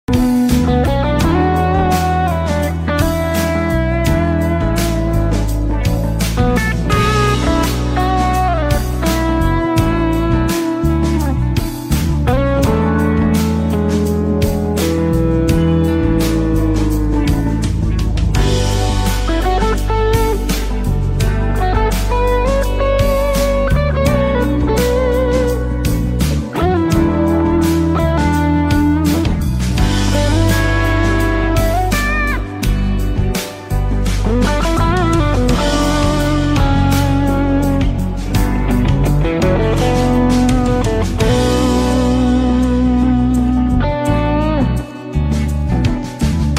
Fun Guitar improvisation sound effects free download